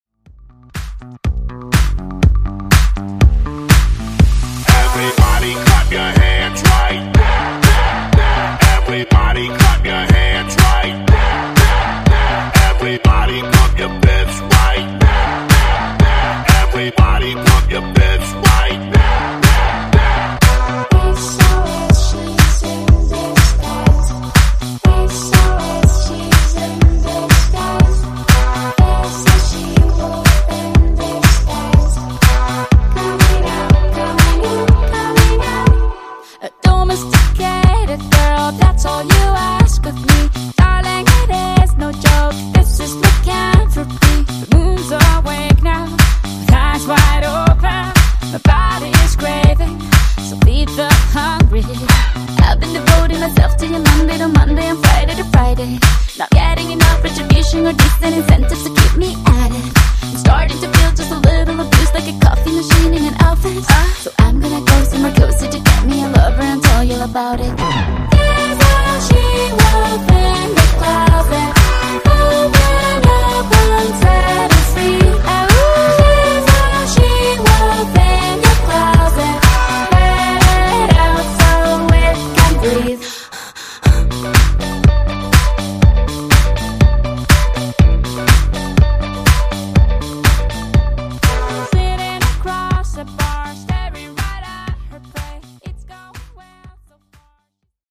Genres: DANCE , RE-DRUM Version: Clean BPM: 122 Time